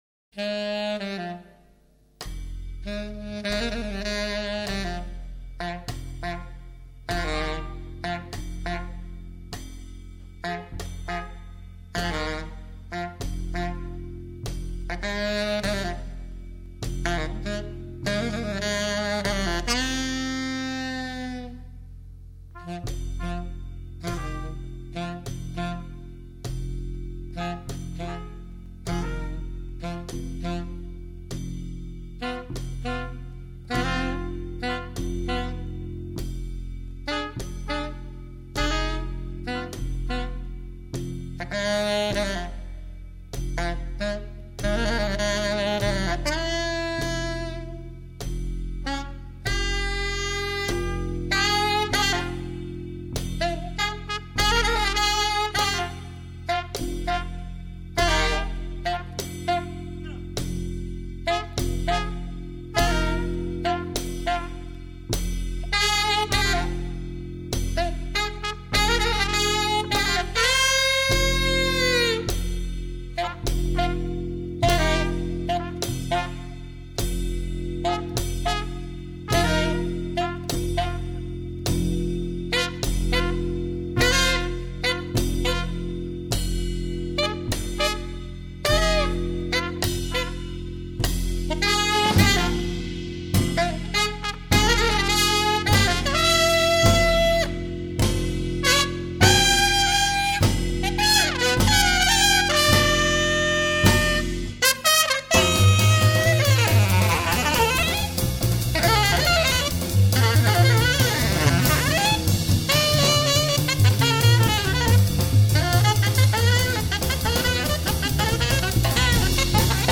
Recorded at the Tampere Jazz Happening, Finland